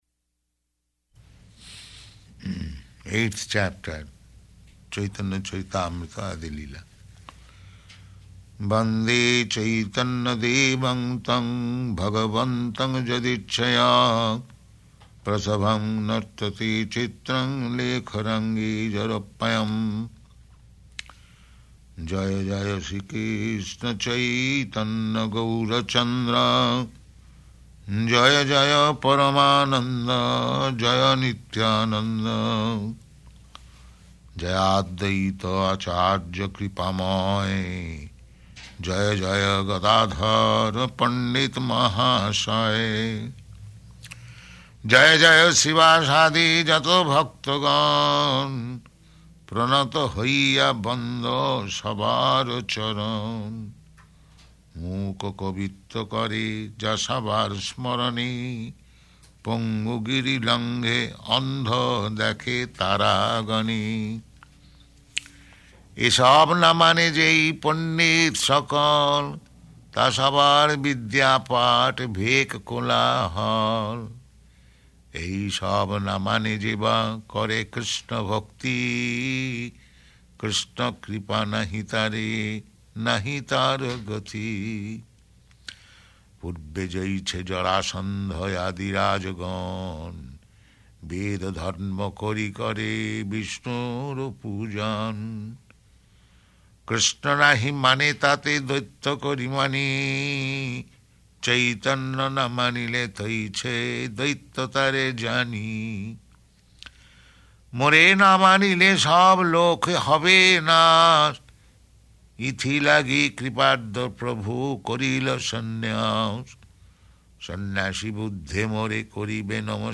Chanting Cc Ādi-līlā 8th Chapter